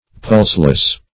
Pulseless \Pulse"less\, a.